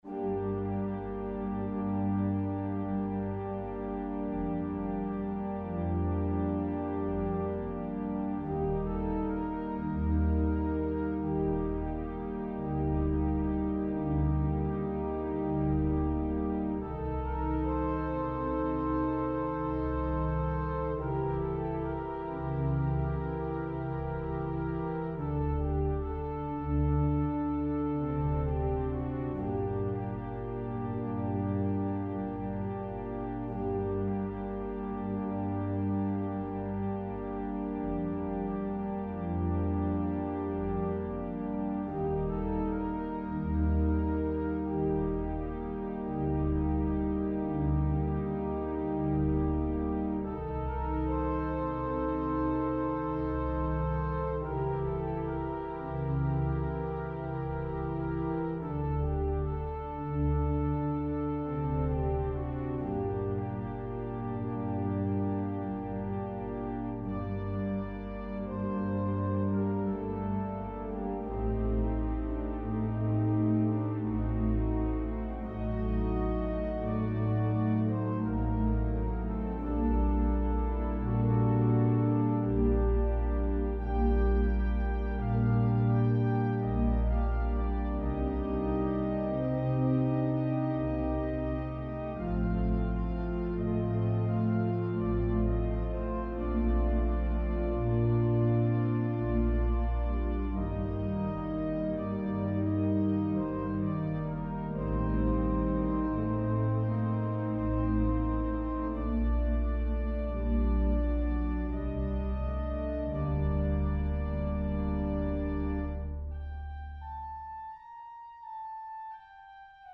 adapted from a fragment of figured bass